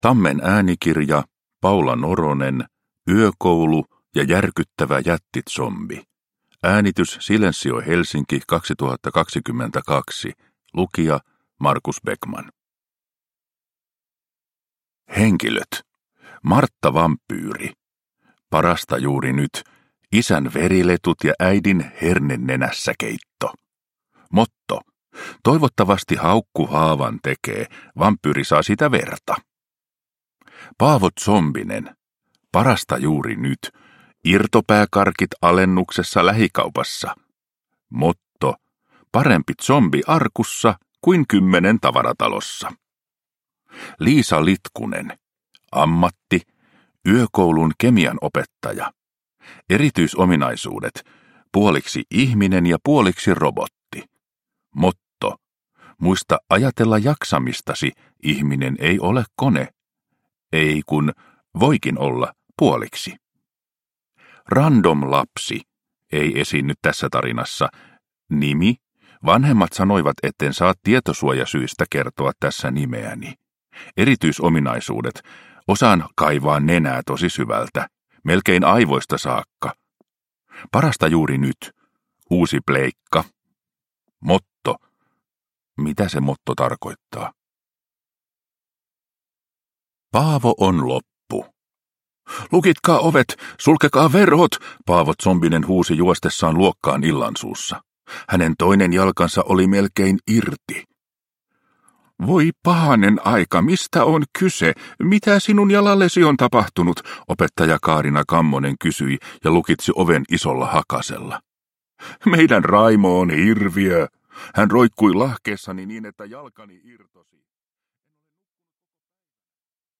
Yökoulu ja järkyttävä jättizombi – Ljudbok